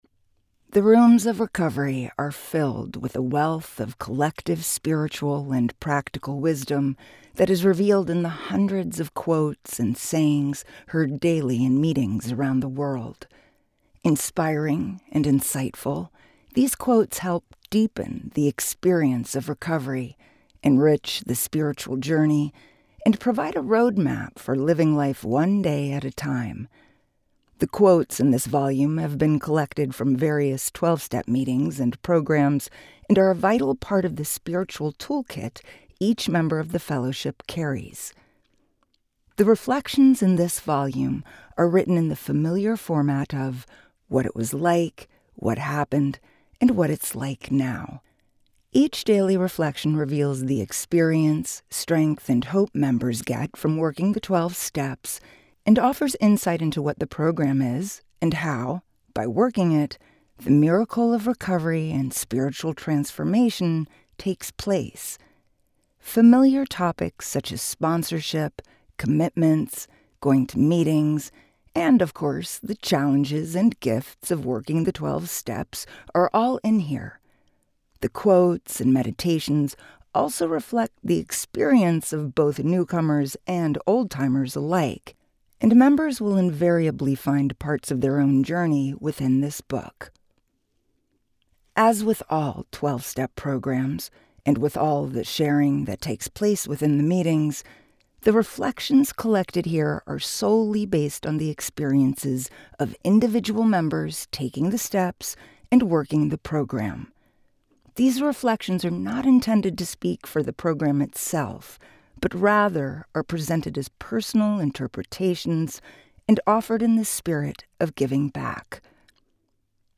wisdom of the rooms audio book